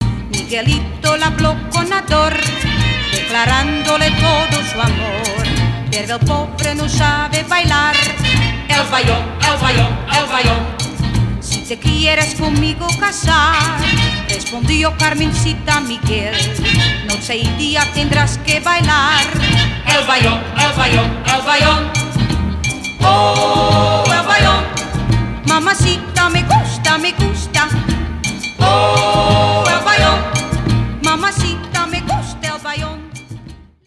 • Качество: 320, Stereo
испанские
50-е
шлягеры